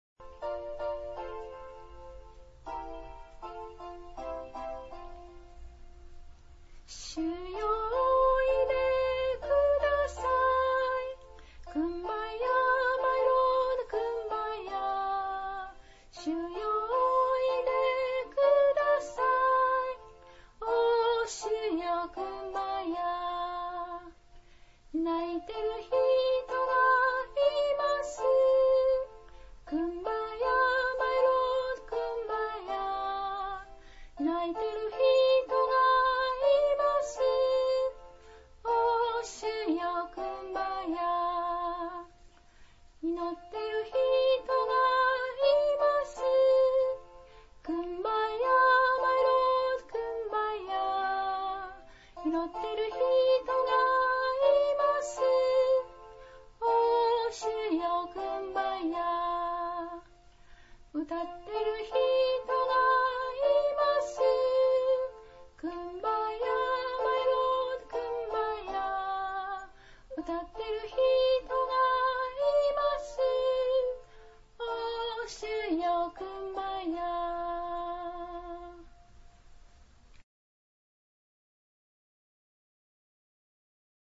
黙22:17, 20 Ⅰコリ16:22 礼拝 応答唱（マラナ・タ） ※視聴できない場合はをクリックしてください。